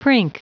Prononciation du mot prink en anglais (fichier audio)
Prononciation du mot : prink